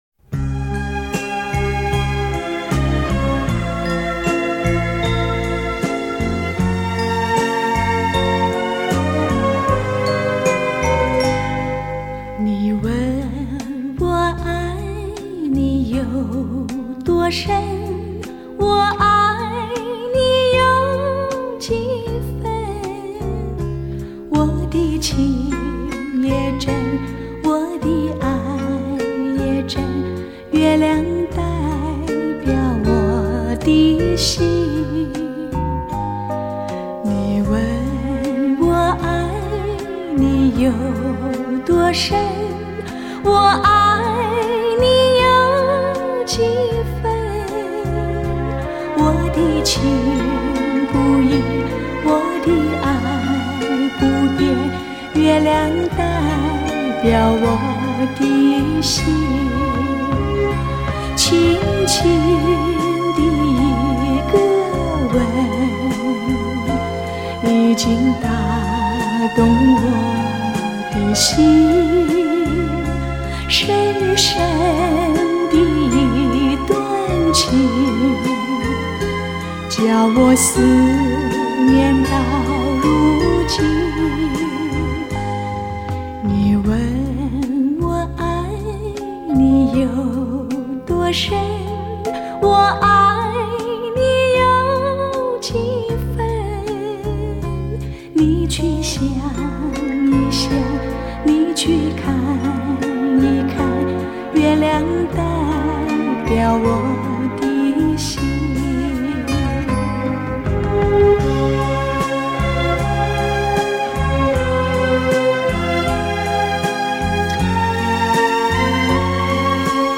将100KHz宽频带/24bit音频信息载入
音色更接近模拟(Analogue)声效
强劲动态音效中横溢出细致韵味